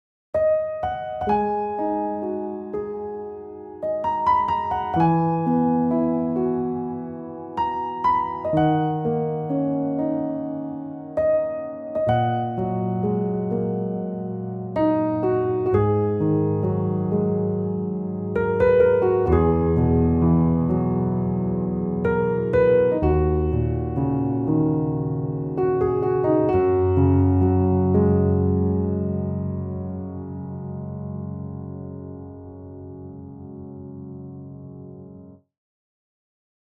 bright felt - felt.mp3